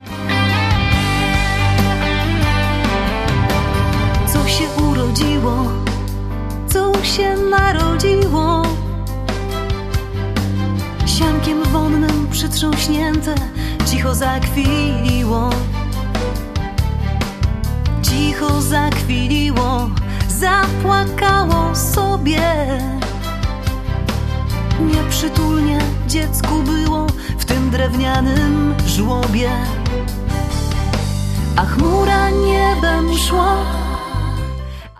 Fortepian, instrumenty klawiszowe
Perkusja, instrumenty perkusyjne
Gitary
Saksofony
Skrzypce